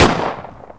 ShotgunFire.wav